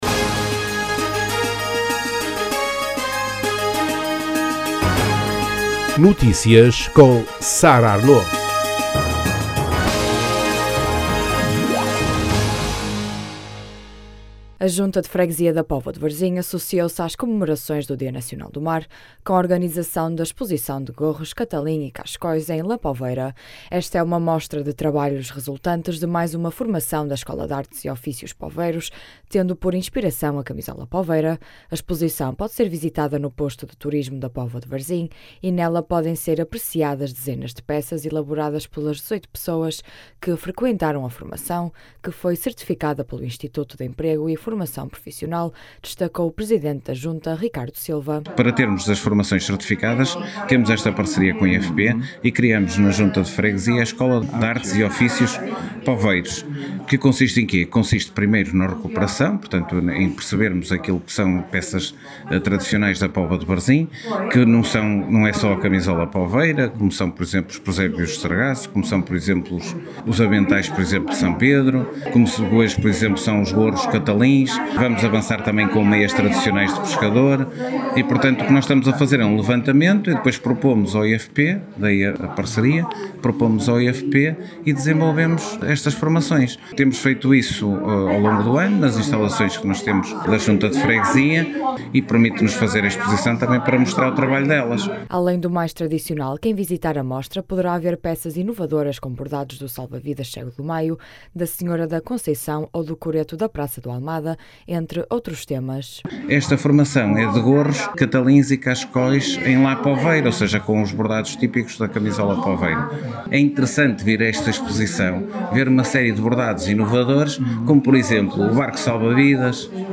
As declarações podem ser ouvidas na edição local.